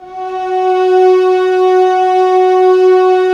Index of /90_sSampleCDs/Roland LCDP13 String Sections/STR_Violas FX/STR_Vas Sordino